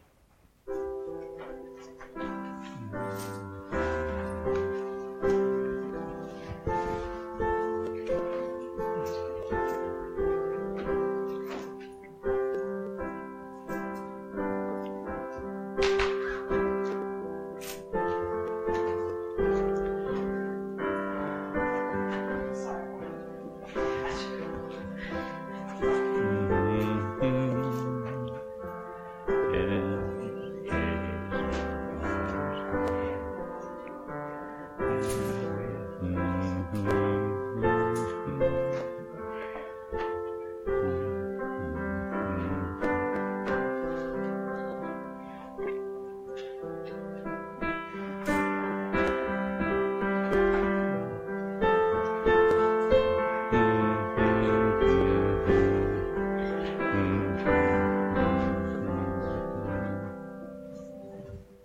Special performances
btn SP-1614-1 Jesus Loves Me This I Know Pianist of the Day 2nd April 2016